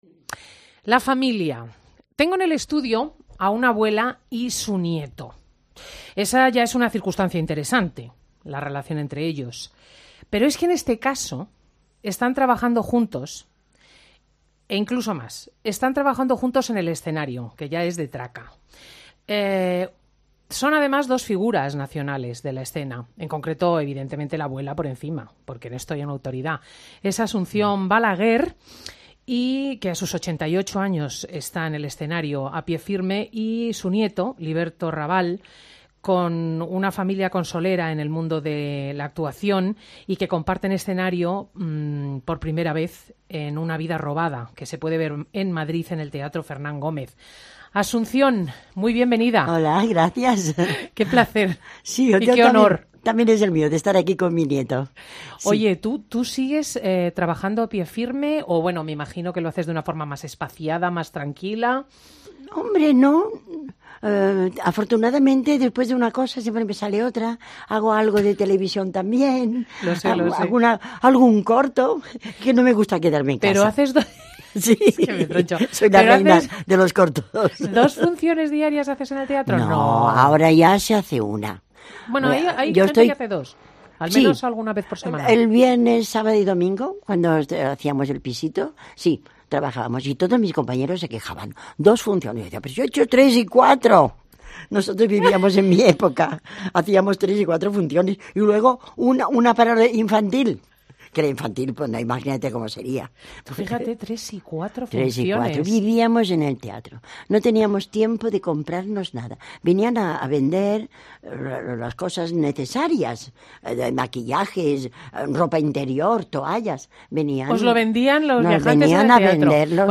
Asunción Balaguer en 'Fin de Semana' (archivo COPE- enero de 2014)